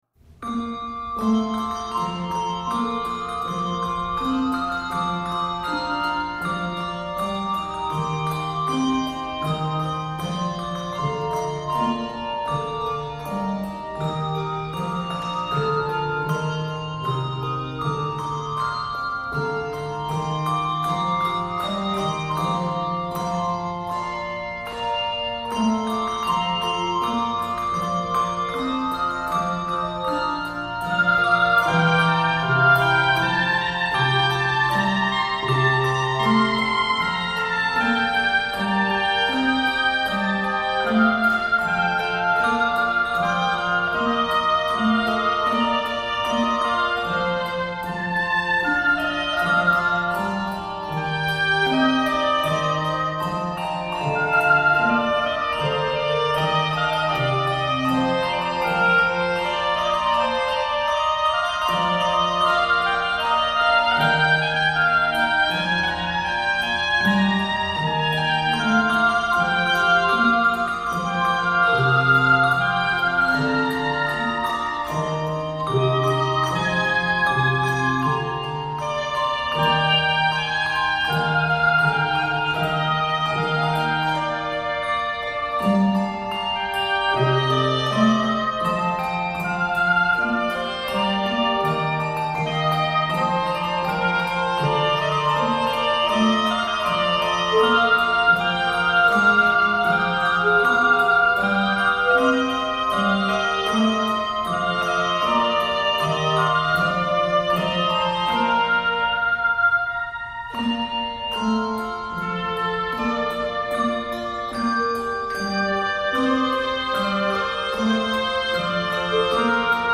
Voicing: Handbells 5-6 Octave